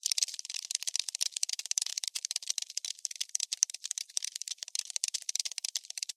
В коллекции есть разные варианты: от шороха лапок до стрекотания.
Звуки таракана: Жужжание жука в спичечном коробке